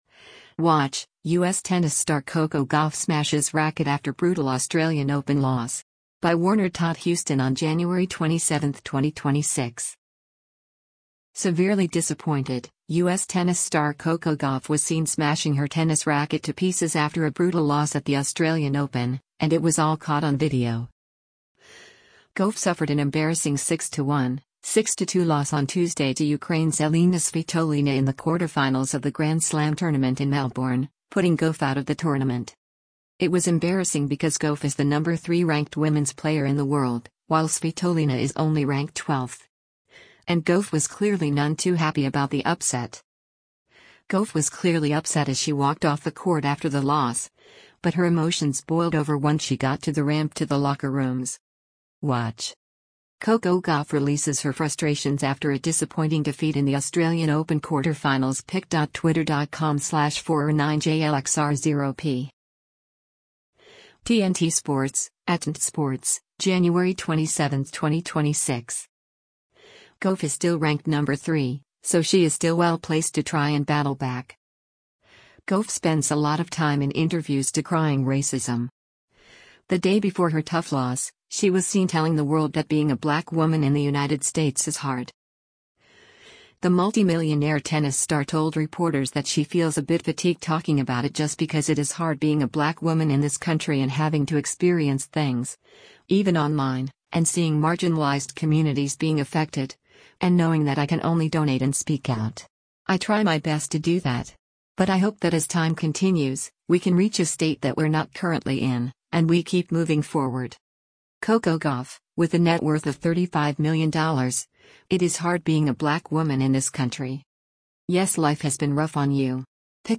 Severely disappointed, U.S. tennis star Coco Gauff was seen smashing her tennis racket to pieces after a brutal loss at the Australian Open, and it was all caught on video.
Gauff was clearly upset as she walked off the court after the loss, but her emotions boiled over once she got to the ramp to the locker rooms.